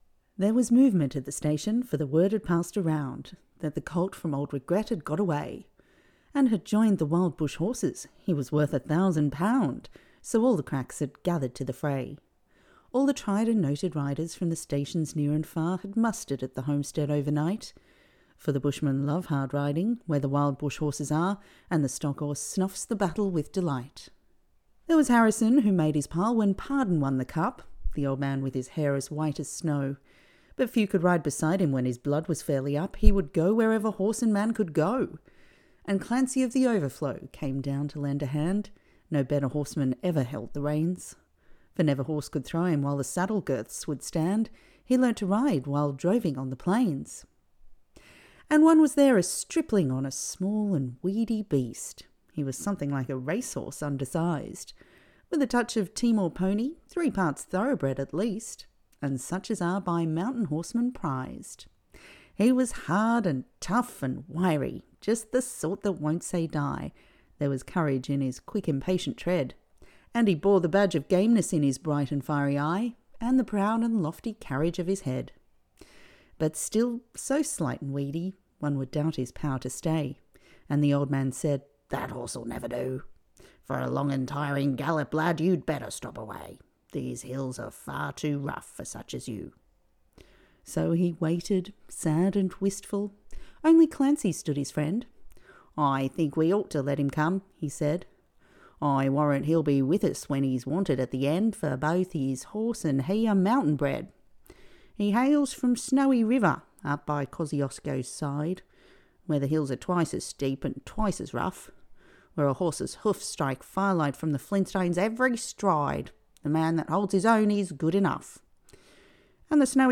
Female
English (Australian)
Adult (30-50)
Natural, female Australian speaking voice - friendly, approachable, warm and easy listening. Also bright, versatile and authoritative.
Narration
All our voice actors have professional broadcast quality recording studios.